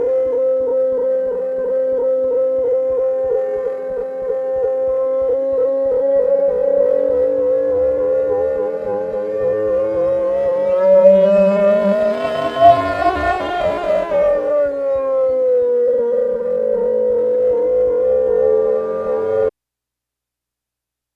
Index of /90_sSampleCDs/E-MU Producer Series Vol. 3 – Hollywood Sound Effects/Science Fiction/Scanners
SCANNER 4-L.wav